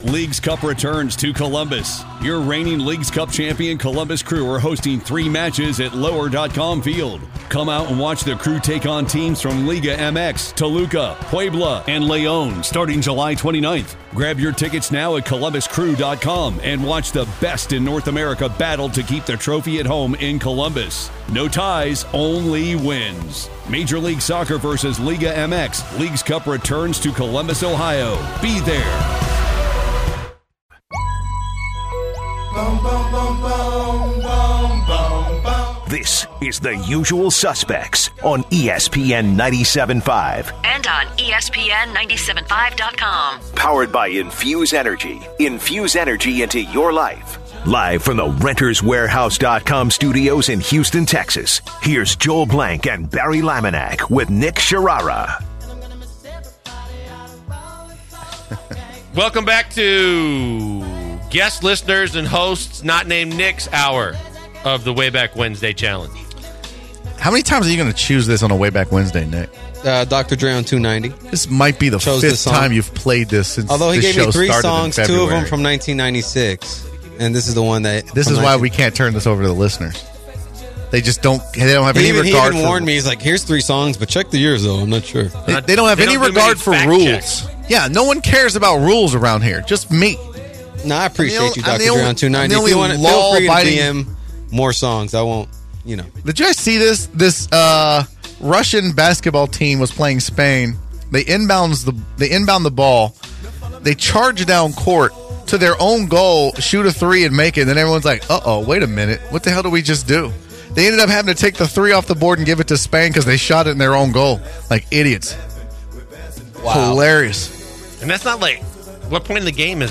They talk about Kyrie Irving and the Cavs, and a caller comes in to talk about how Damian Illiard should possibly go to the Cavs with LeBron. They finish up with some talk about the WNBA, and end on Julio Jones tragic jewelry loss.